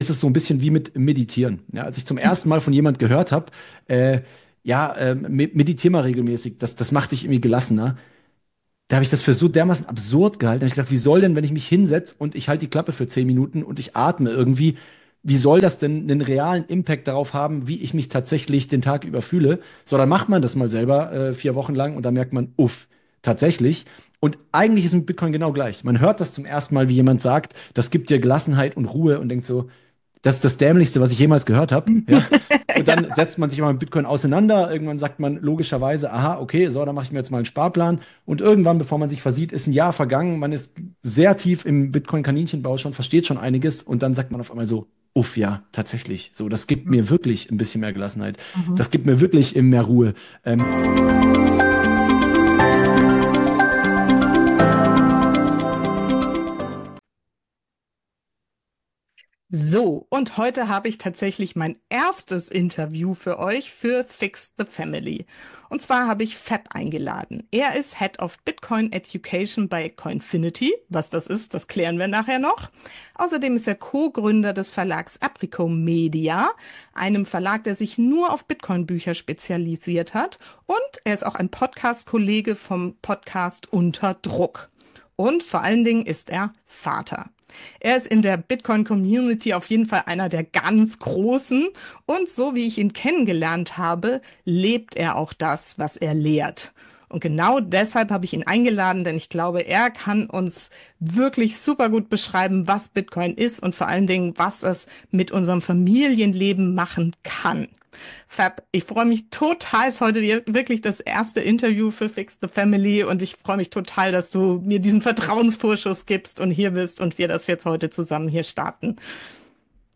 006 - Familien unter Druck: Was Eltern heute wirklich belastet – und was jetzt hilft! Interview